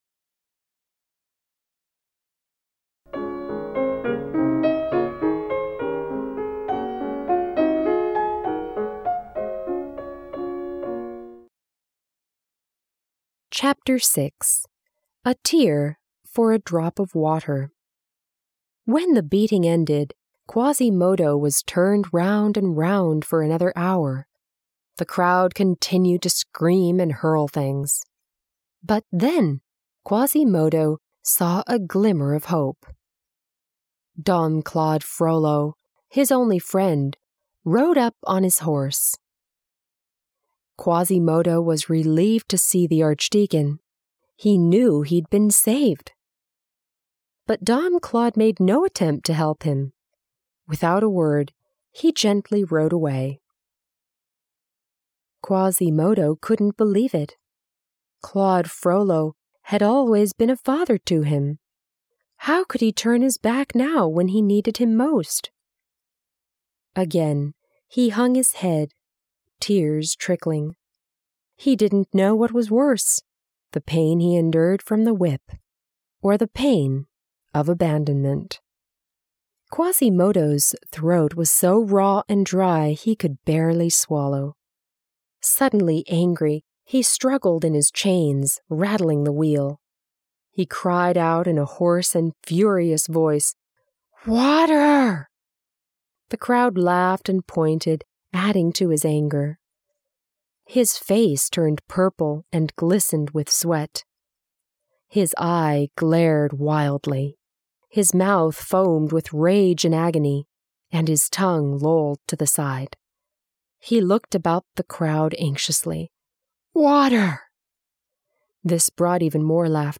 美国教育专家精心编写，儿童文学家黄蓓佳作序推荐，硬壳精装，环保护眼印刷，随书附赠英文有声书